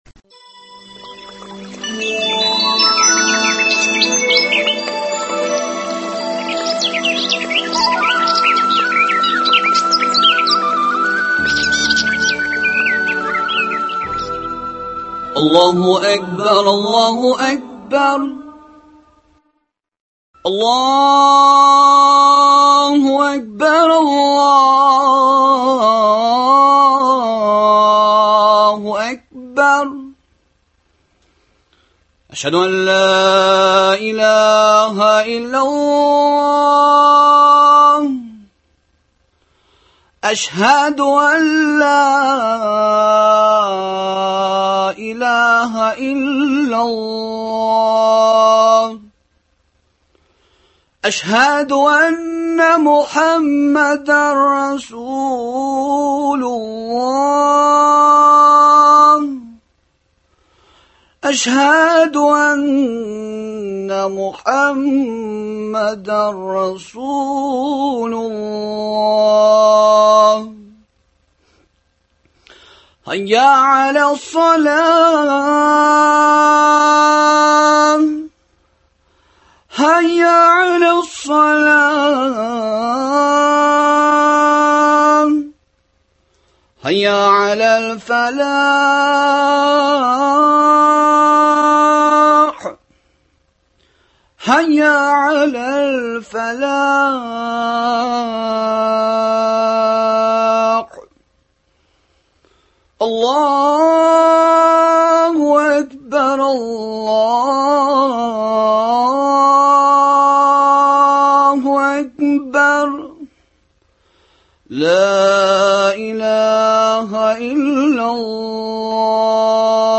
хатын-кызның гаиләдәге урыны, балалар тәрбияләүдәге җаваплылыгы һәм ире белән булырга тиешле мөнәсәбәтләре хакында әңгәмә.